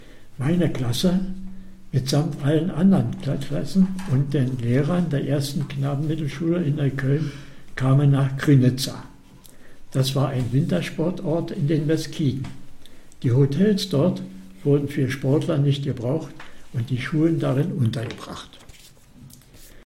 Festival Offenes Neukölln - Lesung